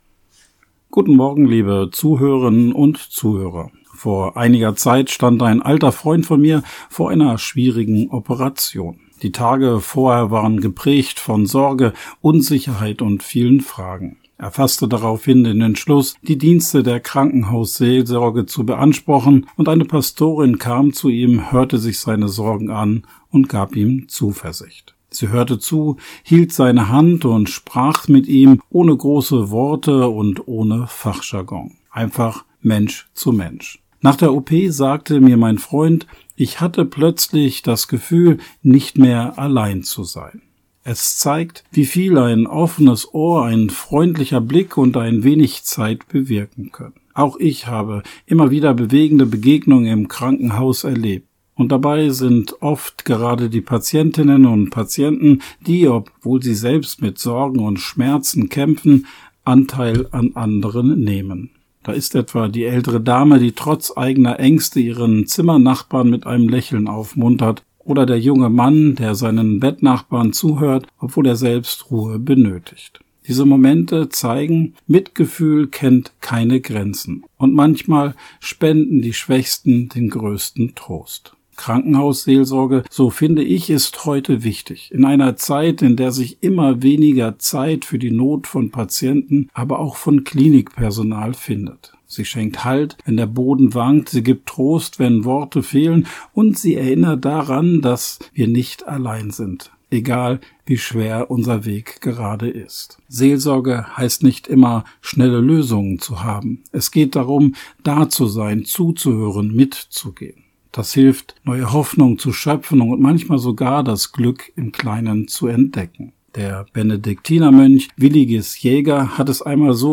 Radioandacht vom 23. Januar